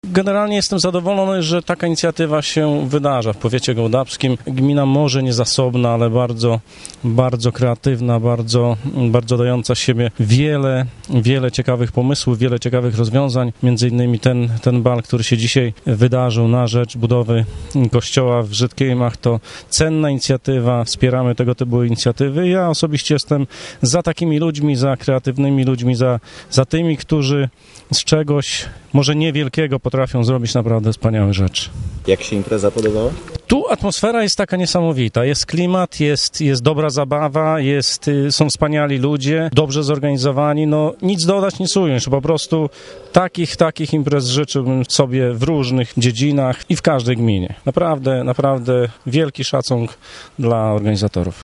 mówi starosta gołdapski Andrzej Ciołek